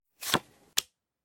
new_card.ogg